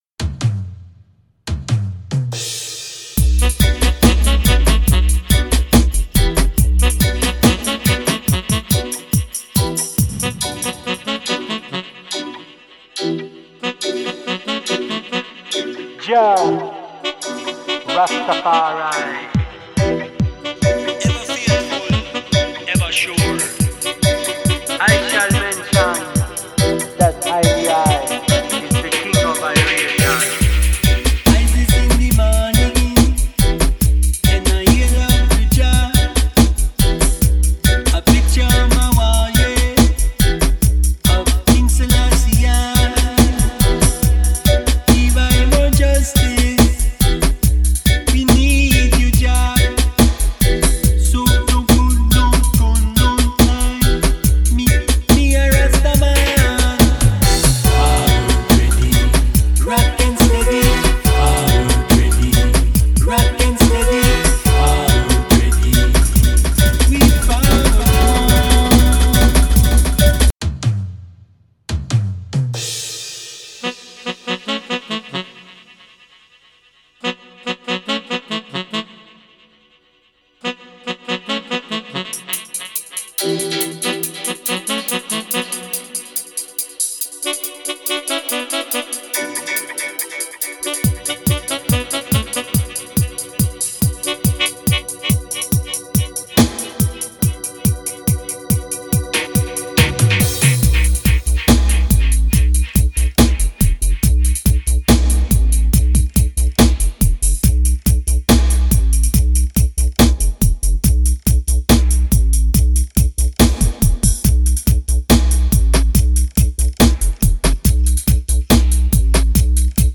Dub Stepper , UK Roots / Modern Roots
Drums, bass, piano
Horns
Percussion